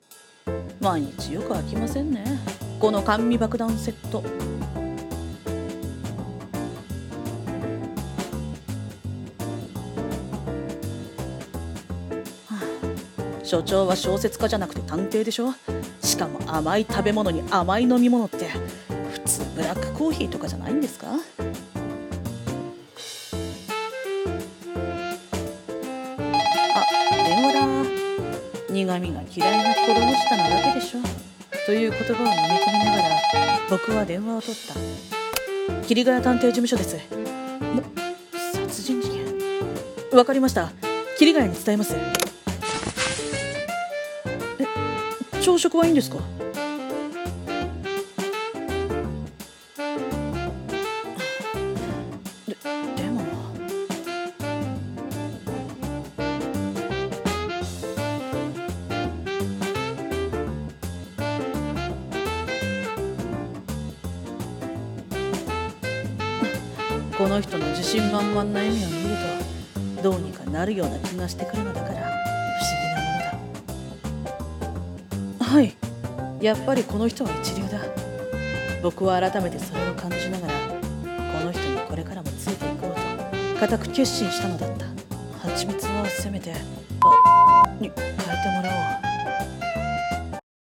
声劇台本